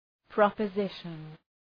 Προφορά
{,prɒpə’zıʃən} (Ουσιαστικό) ● δήλωση ● πρόταση